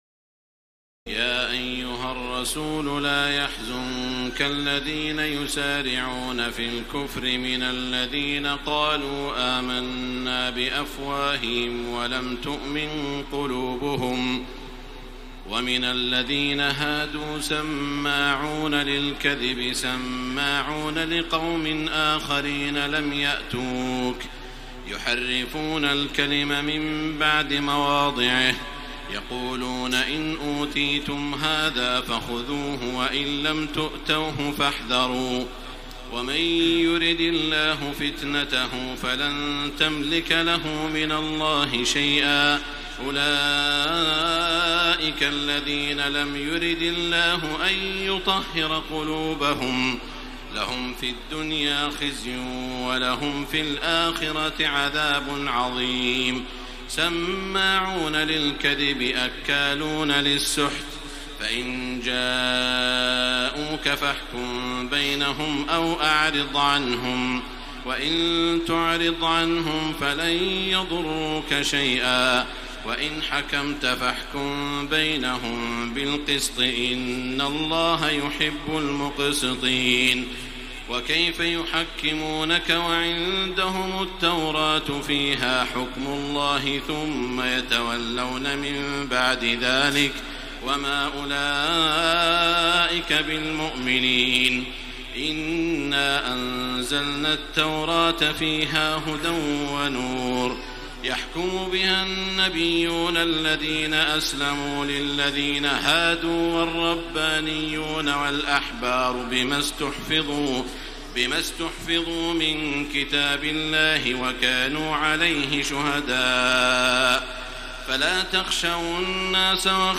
تراويح الليلة السادسة رمضان 1433هـ من سورة المائدة (41-104) Taraweeh 6 st night Ramadan 1433H from Surah AlMa'idah > تراويح الحرم المكي عام 1433 🕋 > التراويح - تلاوات الحرمين